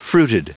Prononciation du mot fruited en anglais (fichier audio)
Prononciation du mot : fruited